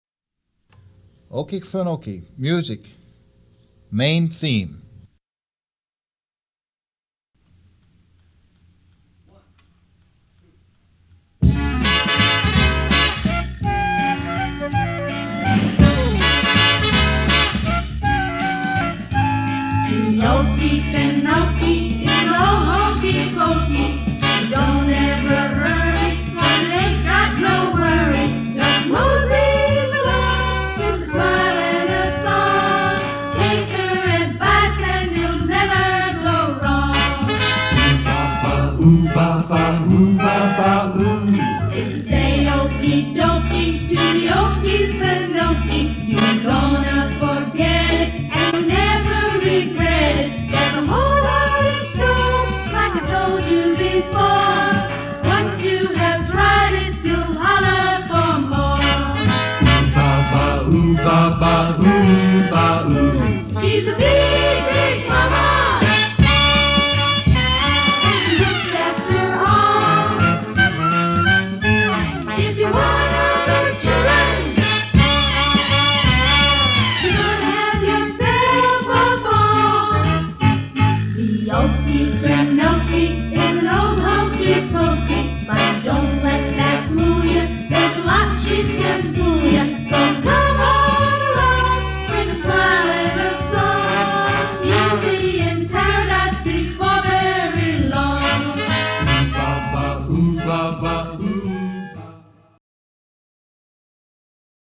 old Okefenokee ride at Six Flags sounded,